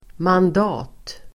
Uttal: [mand'a:t]